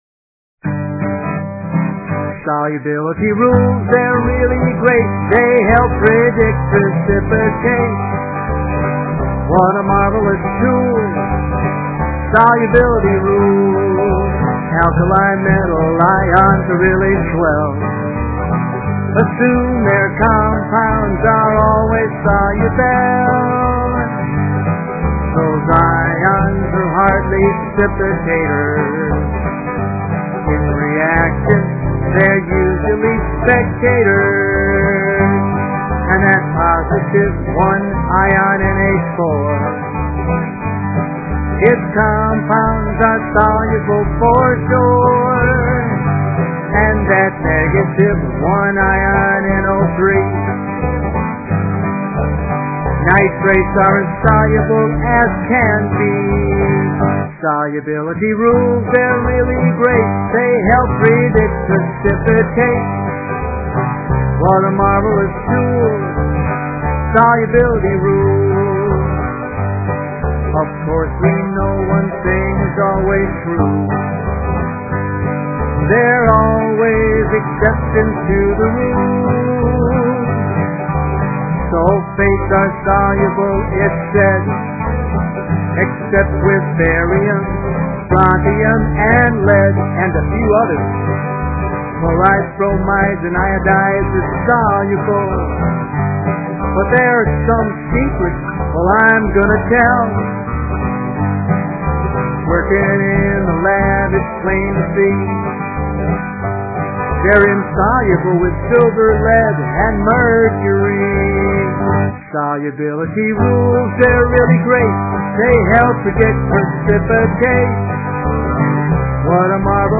Solubility Rules Song MP3